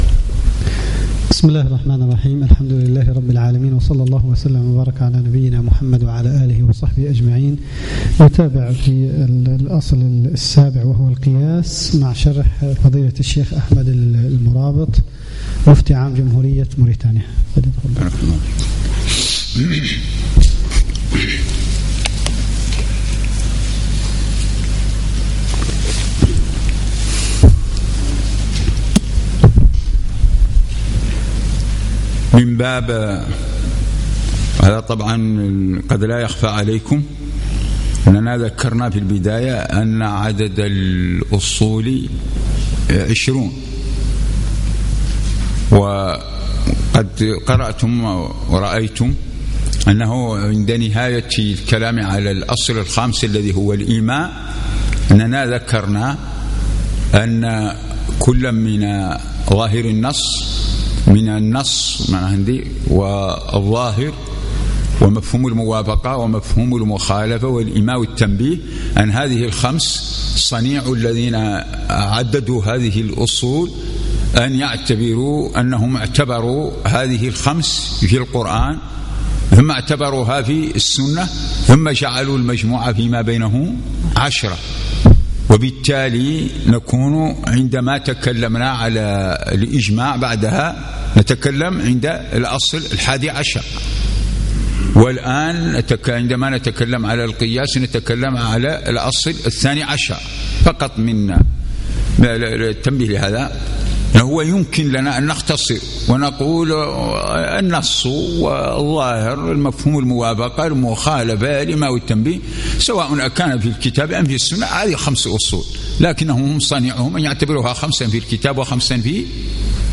صباح الأربعاء 22 جمادى الأولى 1437 الموافق 2 3 2016 بمبني تدريب الأئمة والمؤذنين
الدرس السادس